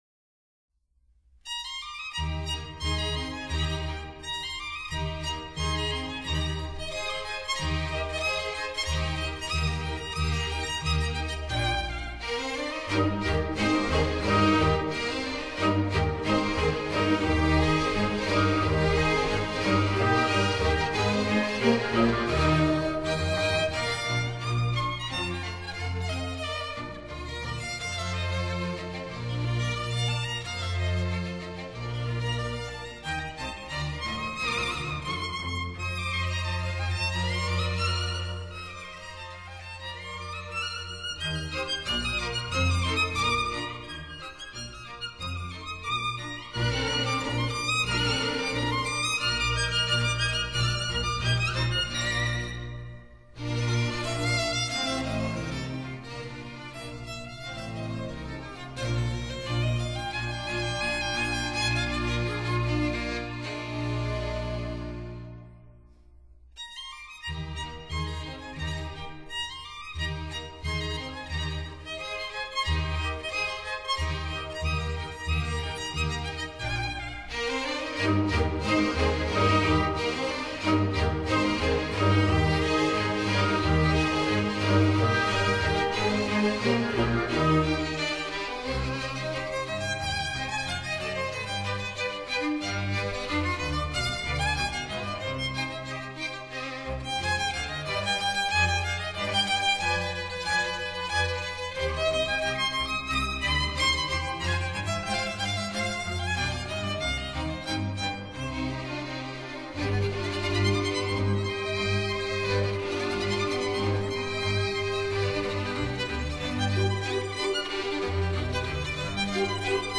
迴旋曲具有活潑熱烈的特性，適合表現歡樂的氣氛，多用於做舞曲。